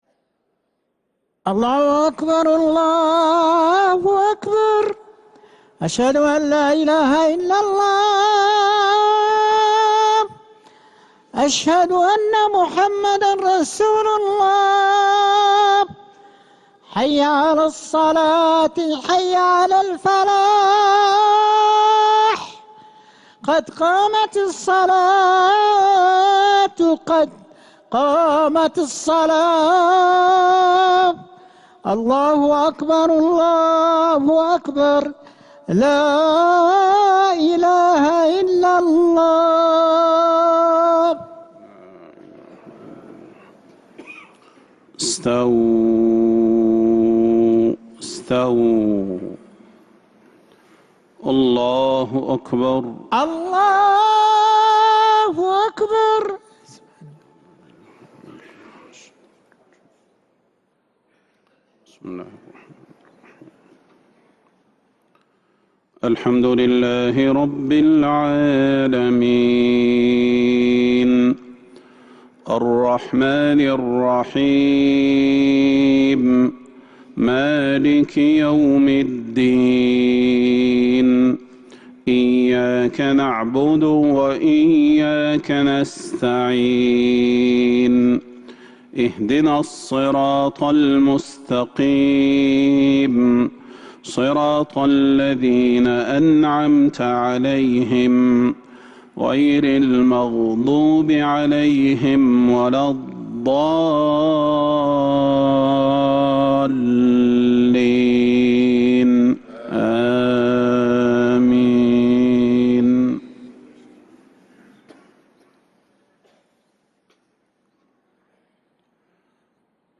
Madeenah Isha - 07th February 2026